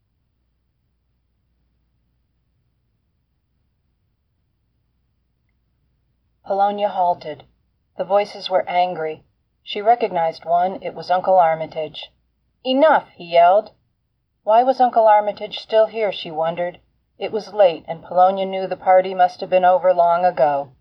Below is a recording on my android.
Every time you say anything there is a wishshsh of noise.
It sounds like a closet with no clothing in it—talking in a box.